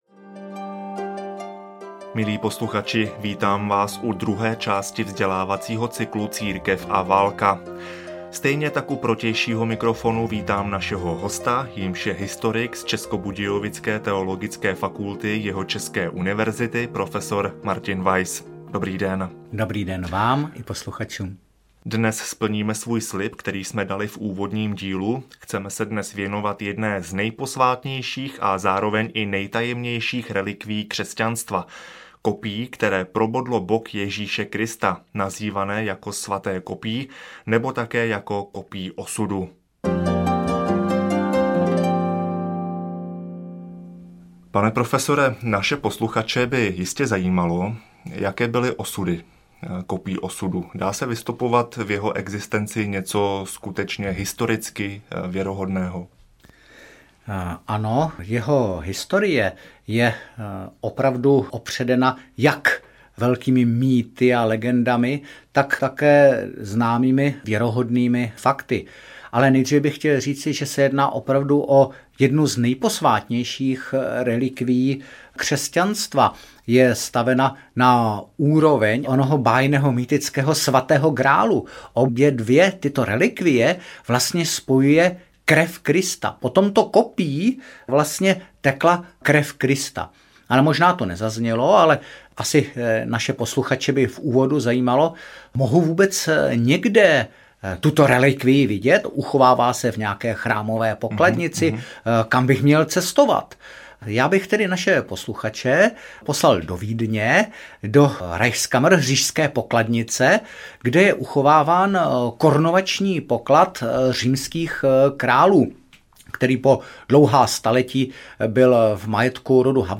Církev a válka audiokniha
Ukázka z knihy